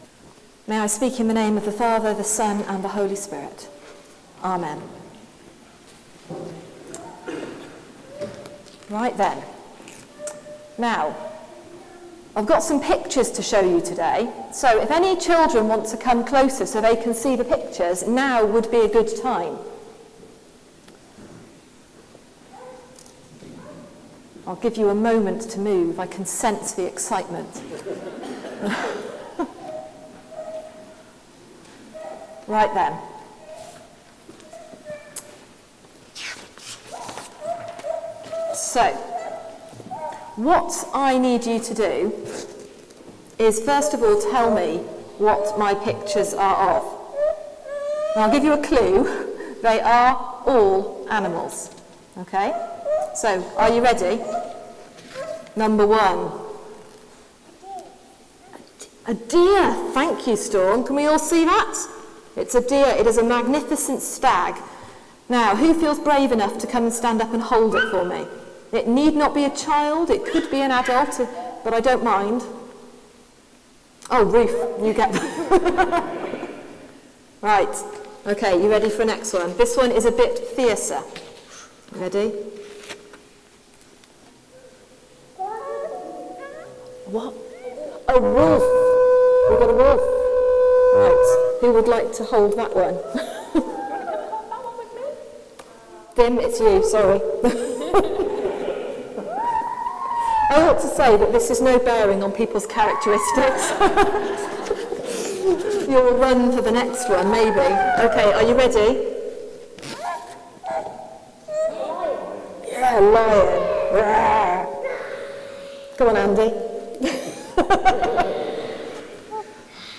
Sermon: All welcomed at God’s table | St Paul + St Stephen Gloucester